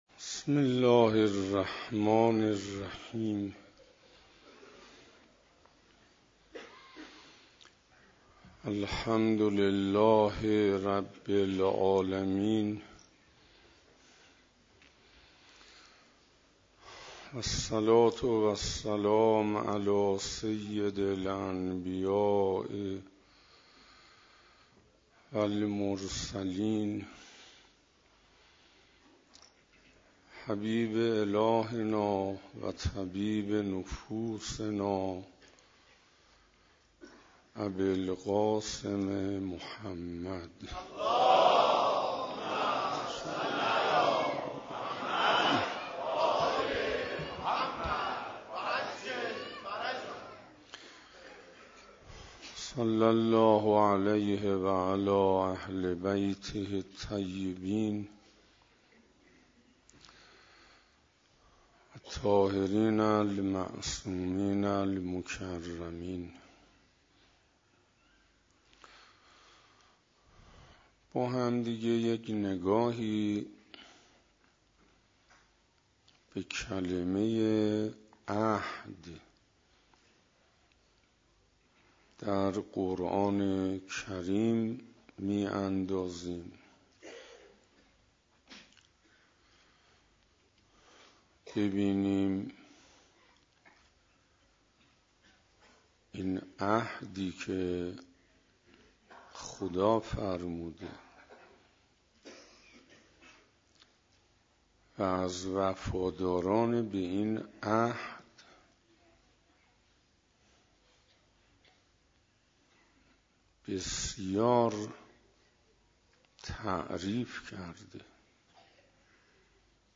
ویژه برنامه روز شهادت حضرت زهرا سام الله علیها - حسینه هدایت